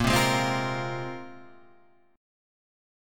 A# 7th Suspended 2nd
A#7sus2 chord {6 3 6 5 6 6} chord